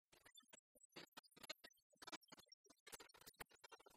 enfantine : comptine
Pièce musicale inédite